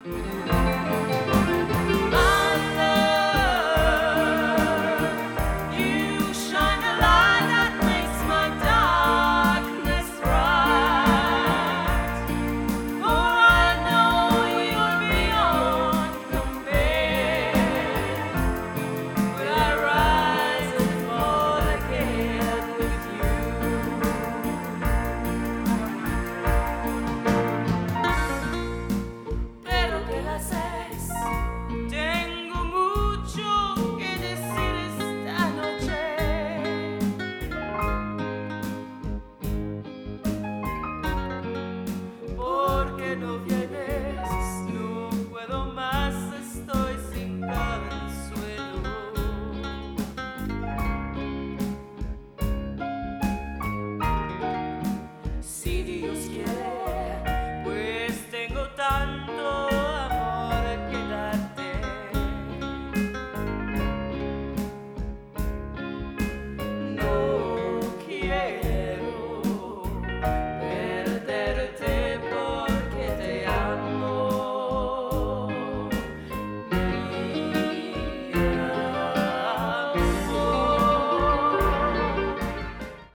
Guitares: Électrique / Acoustique
Piano / Orgue